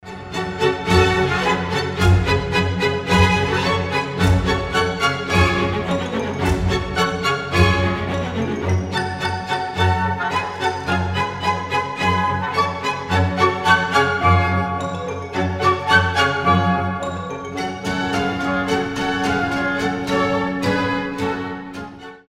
• Качество: 320, Stereo
без слов
скрипка
озорные
оркестр